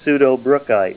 Help on Name Pronunciation: Name Pronunciation: Pseudobrookite + Pronunciation
Say PSEUDOBROOKITE Help on Synonym: Synonym: ICSD 69038   PDF 41-1432